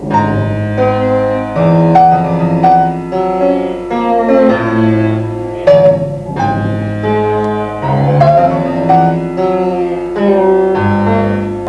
Soundbeispiel HyperKult III 'Sound' Page
Das musikalische Material l�uft �ber ein Effektger�t, einen Verst�rker zu den Boxen. Der Eingriff der H�rer geschieht am Effektger�t; dieses wird durch die Handschuhe gesteuert.
Indem der Daumen auf die Fingerkuppen dr�ckt, werden andersartige Kombinationen der Effekte: Nachhall, Hallraum, Verzerrung, Echo usw. angew�hlt.